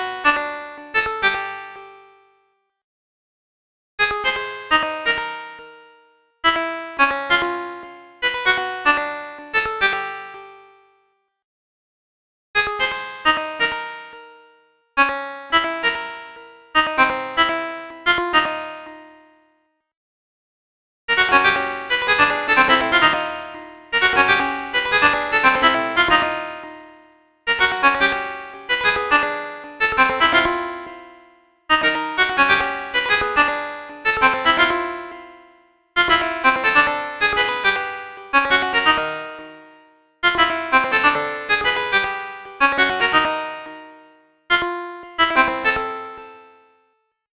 description: Explore serialism in the spirit of Arnold Schönberg and others
The idea is that each note, clock division, and amplitude is used once per bar.